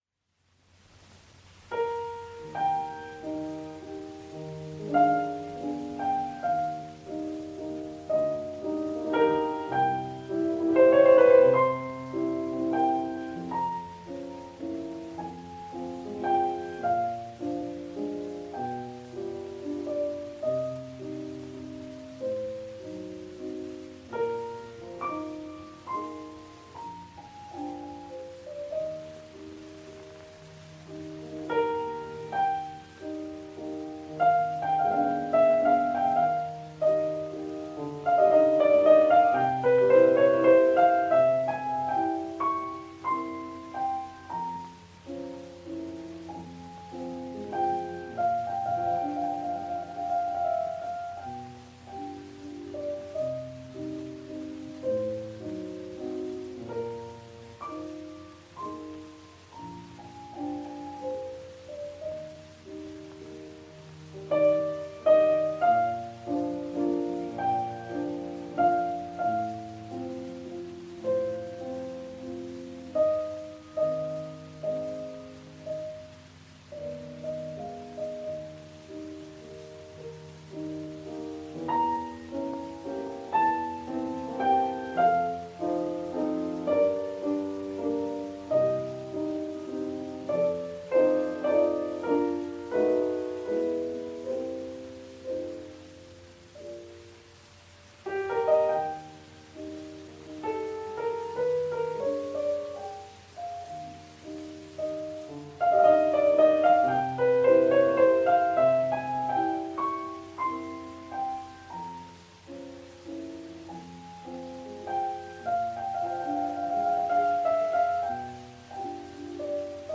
系列名稱：歷史錄音　/　偉大鋼琴家系列
音樂類型：古典音樂